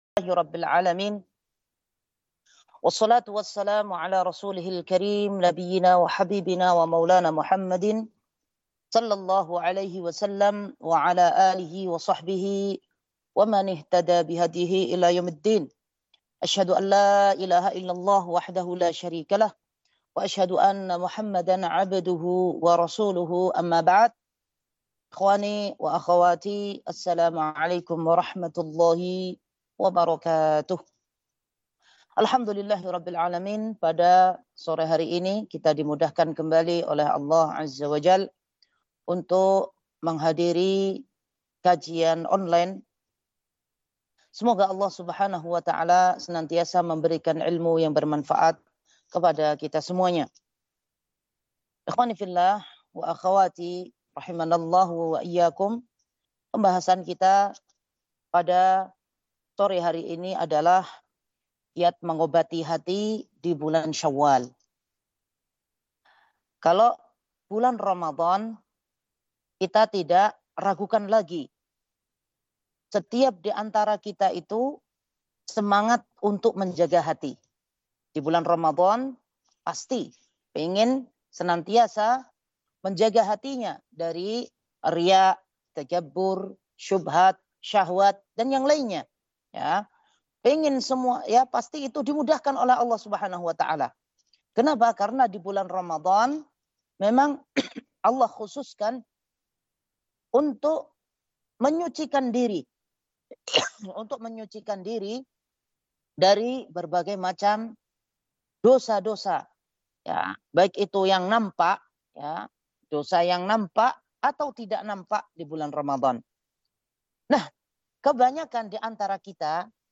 Kajian Online – Teams Awqaf Wakra, 19 Syawal 1445 / 28 April 2024